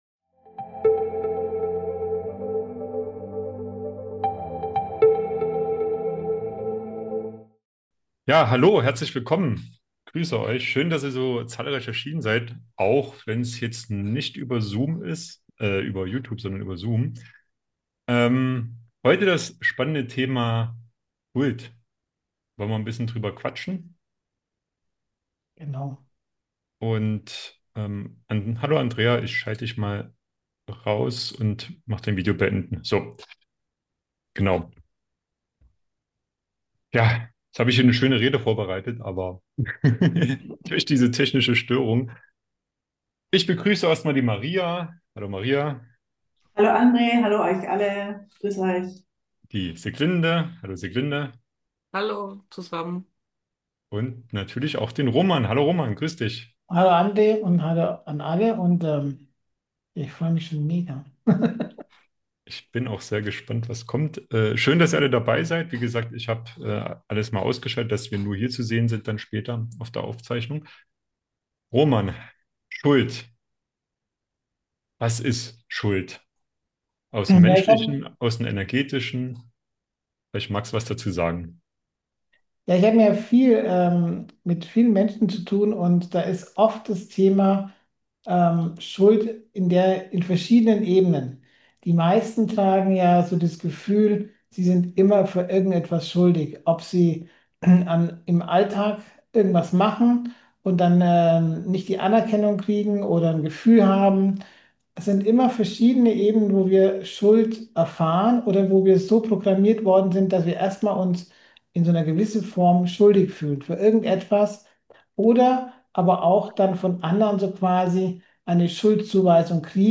In dieser besonderen Live-Aufzeichnung